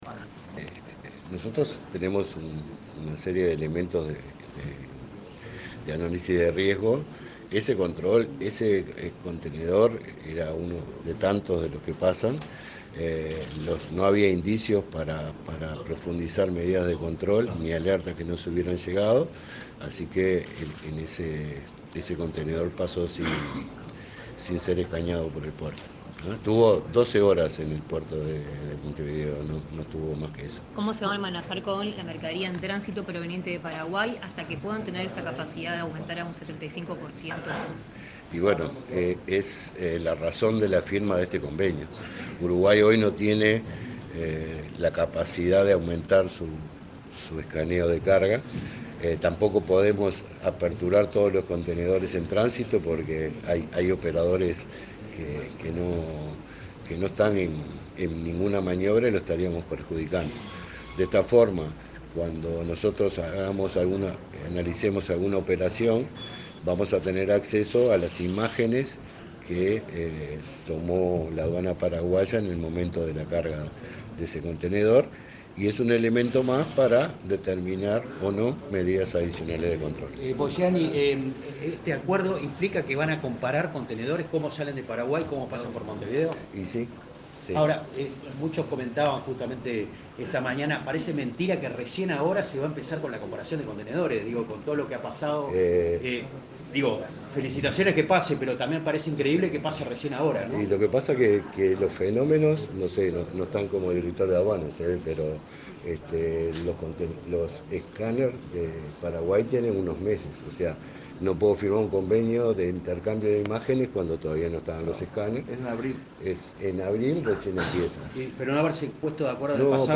Declaraciones de autoridades de Aduanas